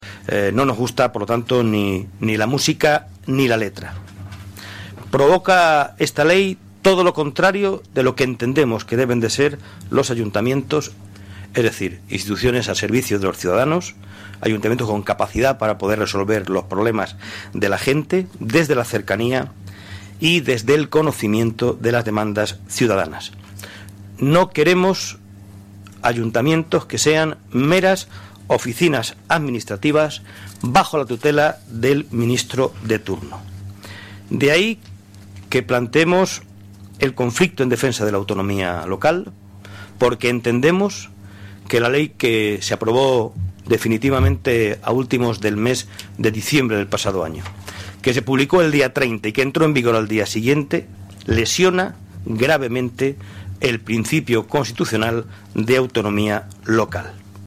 Fragmento de las declaraciones de Gaspar Zarrías después de reunirse con los alcaldes y grupos parlamentarios con los que se va a recurrir al Tribunal Constitucional la reforma de la administración local 7/02/2014